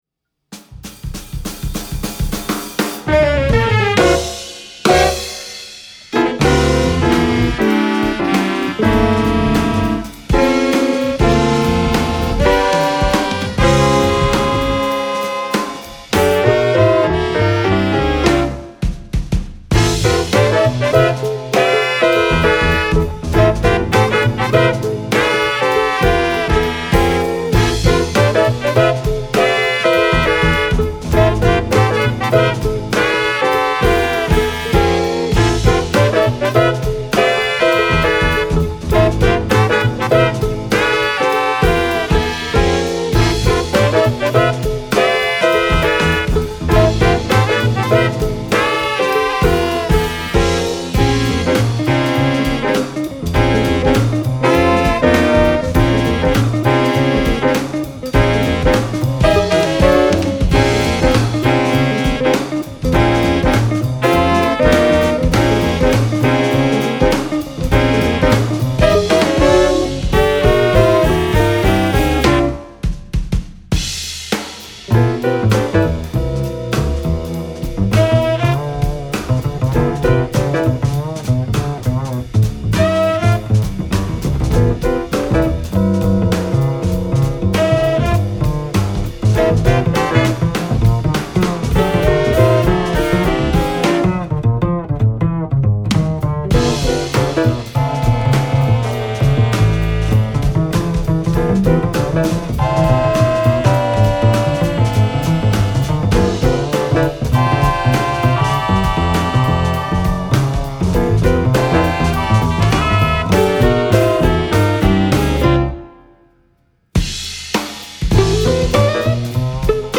guitar
saxophones